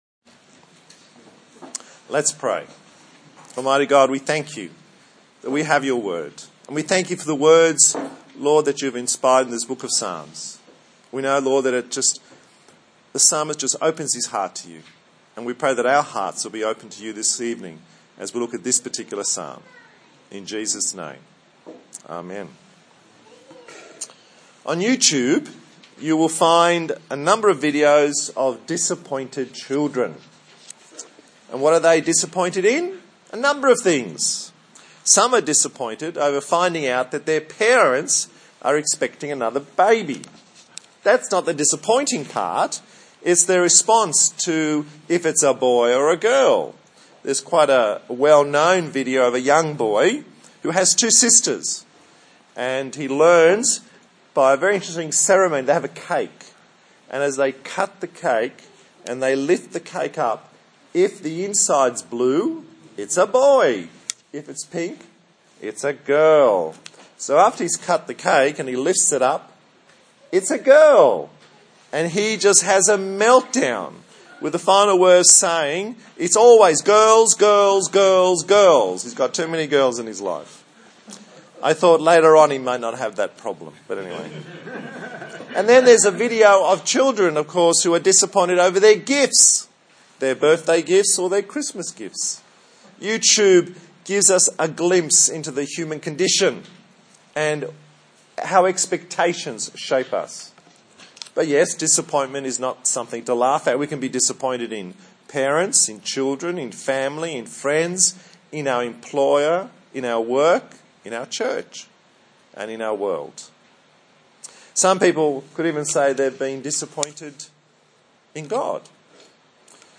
Service Type: TPC@5 A sermon